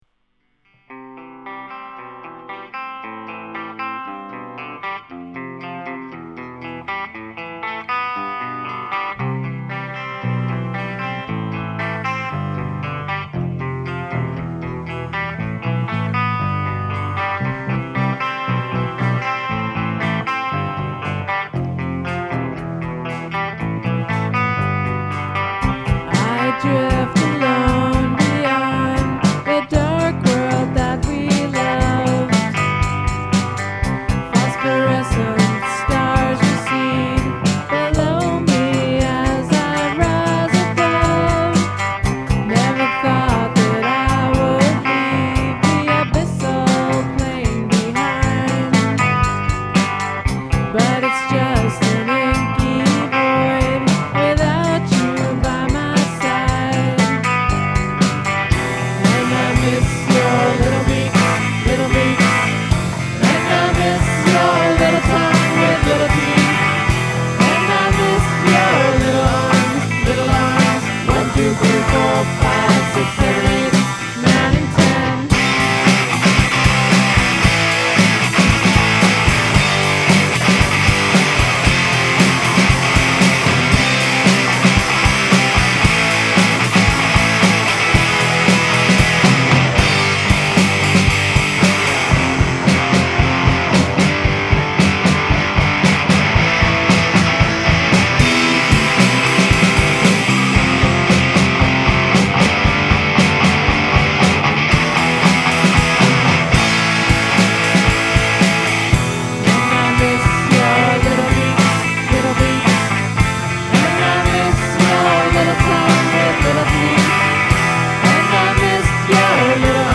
rhythm guitar